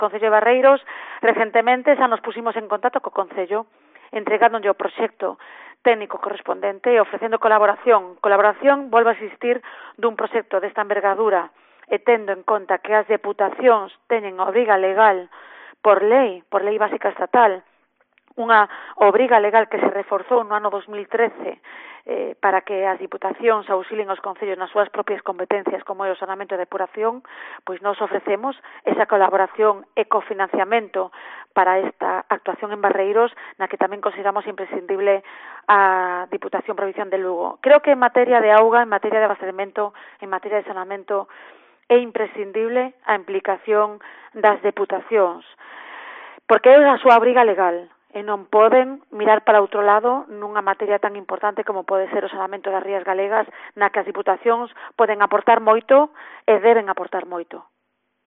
Declaraciones de la conselleira de Infraestructuras sobre la depuradora de Barreiros